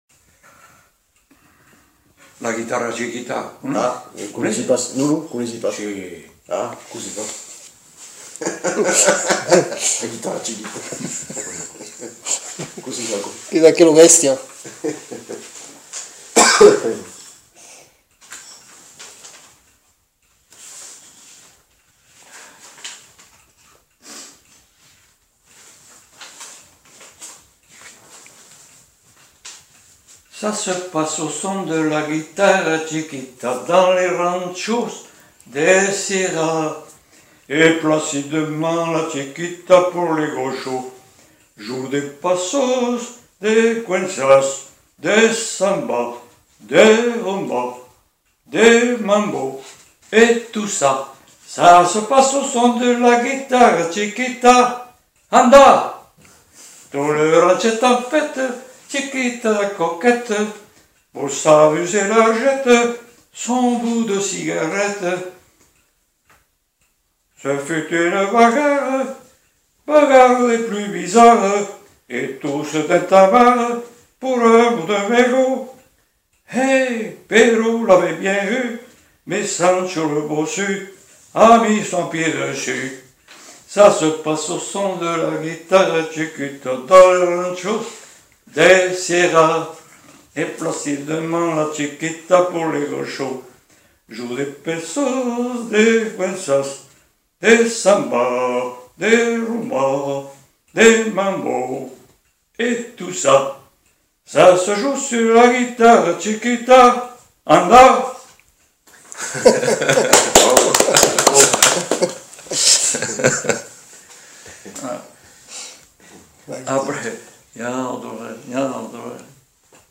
Lieu : Le Faget
Genre : chant
Effectif : 1
Type de voix : voix d'homme
Production du son : chanté
Danse : marche
Description de l'item : fragment ; 2 c. ; refr.